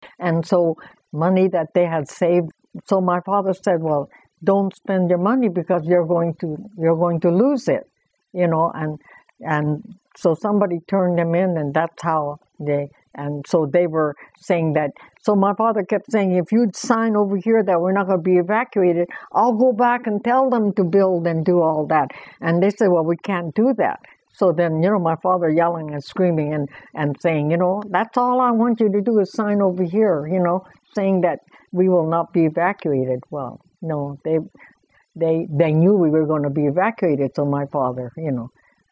This was one of the longest interviews I've conducted so far.